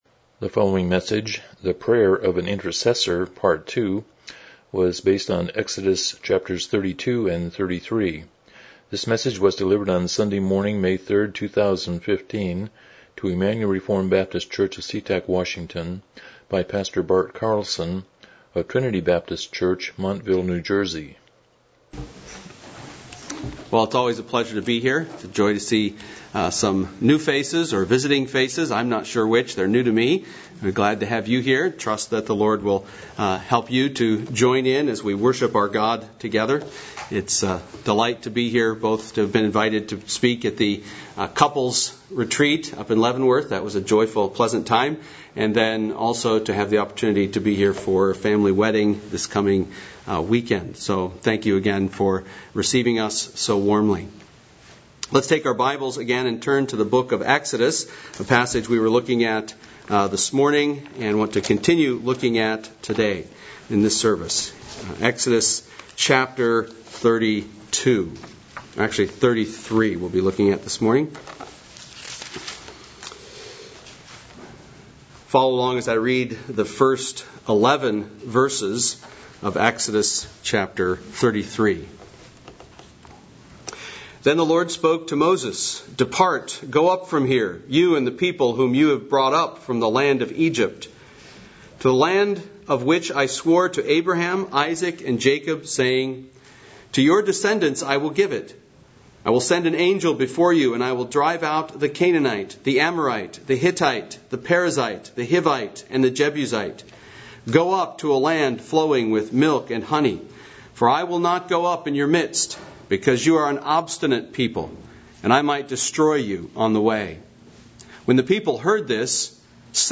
Miscellaneous Service Type: Morning Worship « The Prayer of an Intercessor